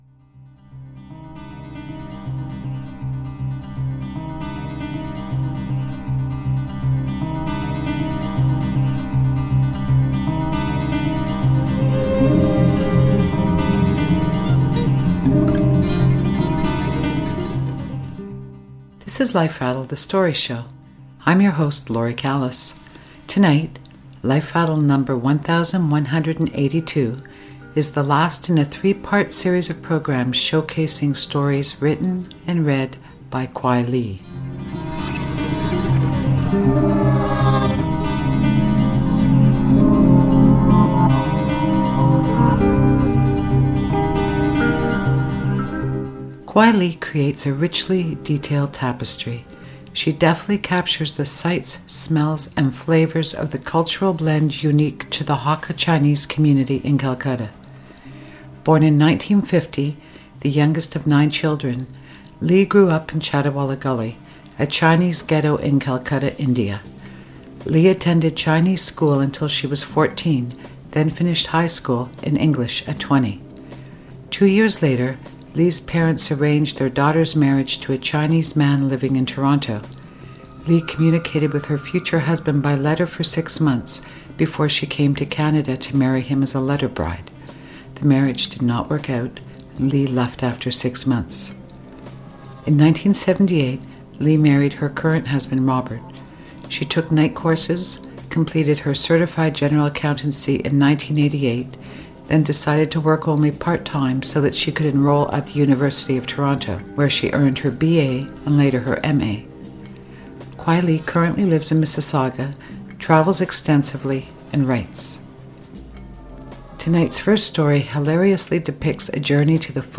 reading her stories